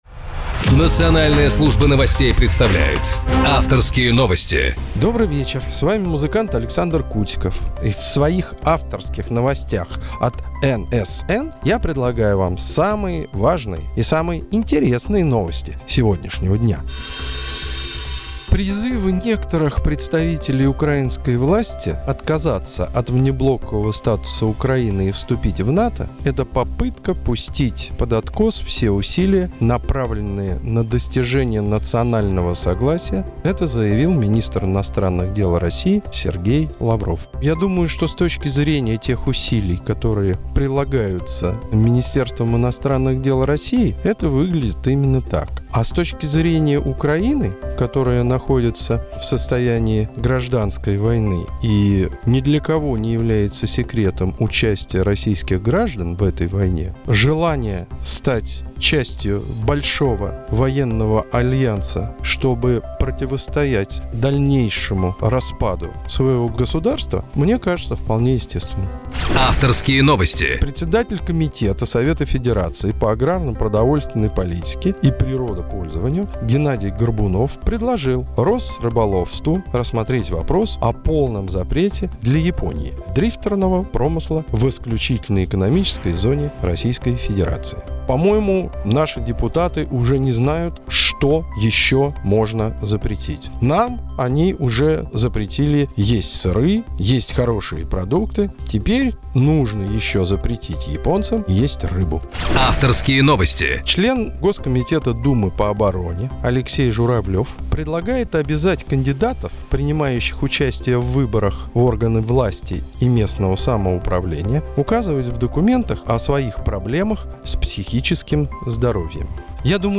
Александр Кутиков 04.09.2014 на радио "Национальная служба новостей" НСН ФМ в передаче "Авторские новости"